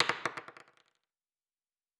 pgs/Assets/Audio/Fantasy Interface Sounds/Dice Single 8.wav at master
Dice Single 8.wav